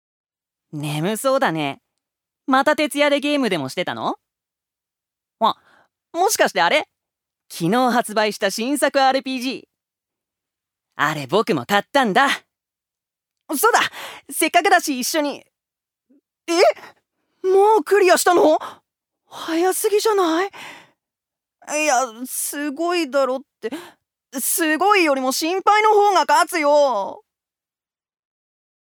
所属：男性タレント
セリフ３